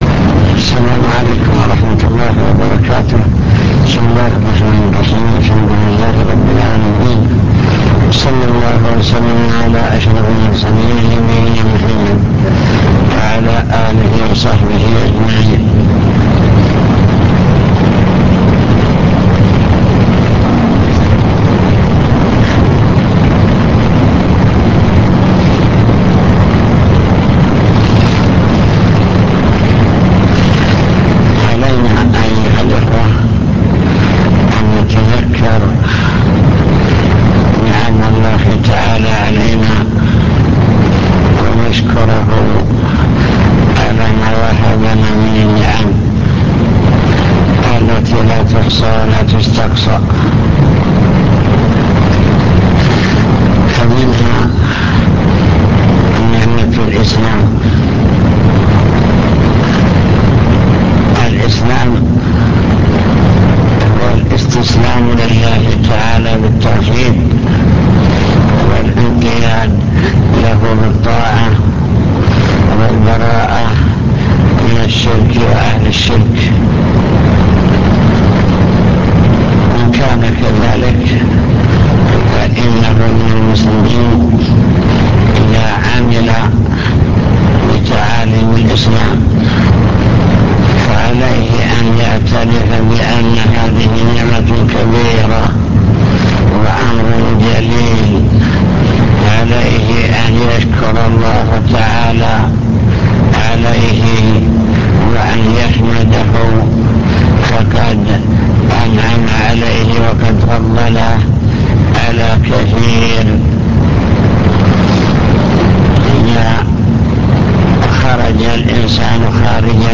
المكتبة الصوتية  تسجيلات - محاضرات ودروس  توجيهات سلاح الحدود